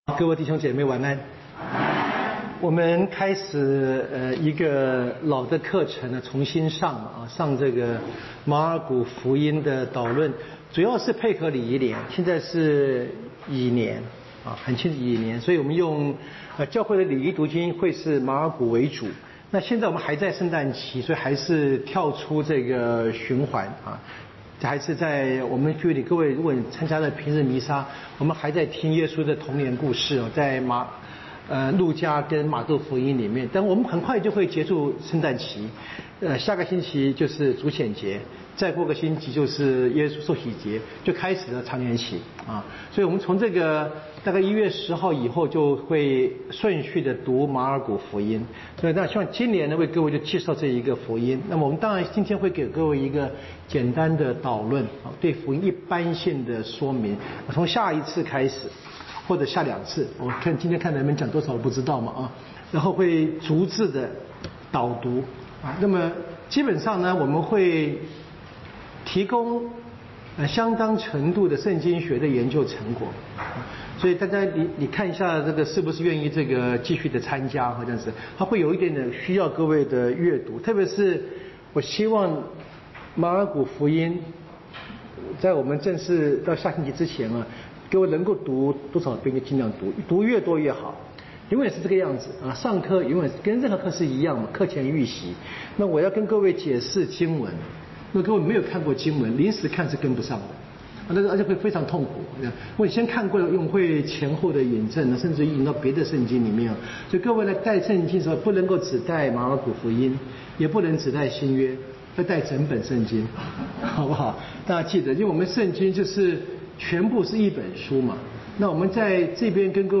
【圣经讲座】